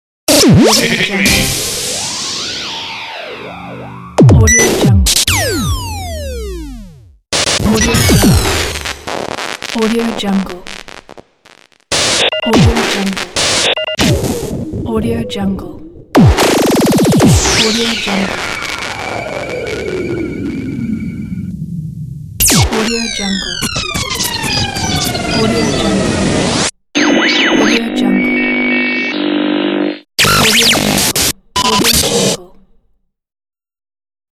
دانلود افکت صوتی صدای تصویربرداری رادیویی
یک گزینه عالی برای هر پروژه ای است که به انتقال و حرکت و جنبه های دیگر مانند شکن، پهپاد و افکت نیاز دارد.